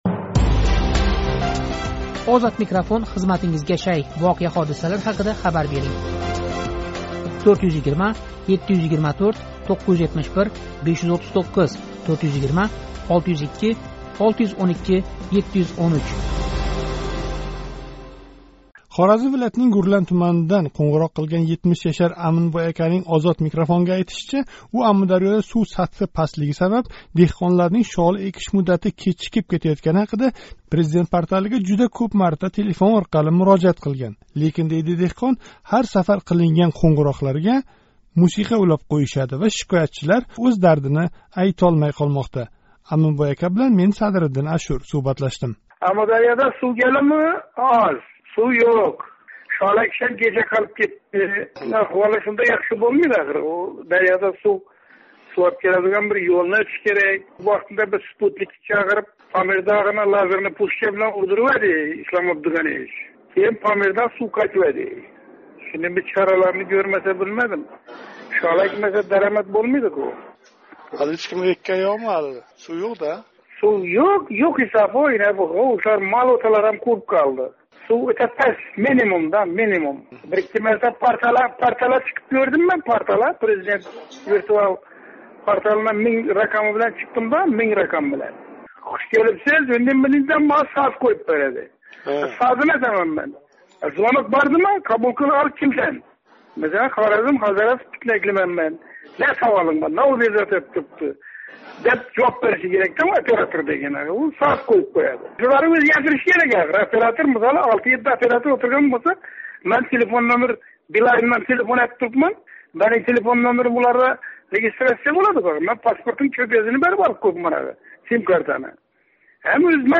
Хоразмлик шоликор: "Шикоят қилайлик десак, Портал телефонларини ҳеч ким кўтармаяпти"